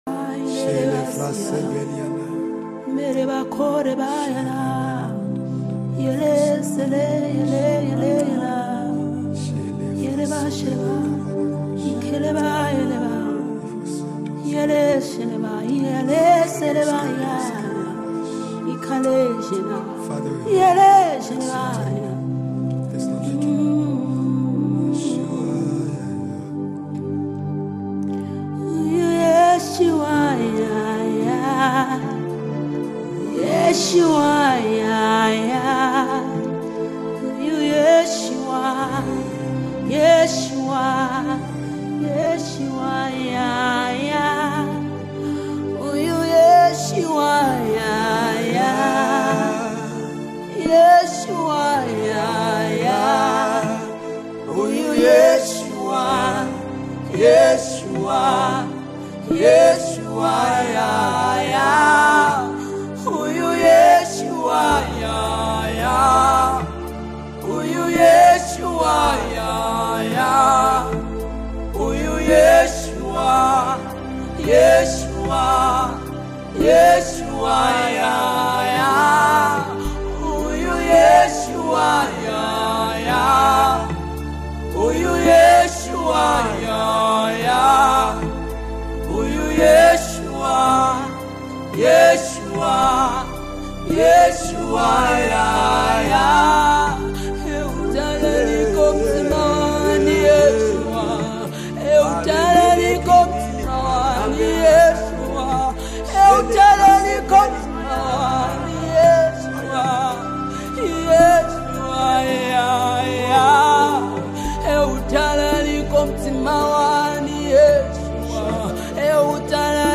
powerful Zambian deep worship song
A powerful and anointed worship song that glorifies Jesus
📅 Category: Zambian Deep Worship Song 2025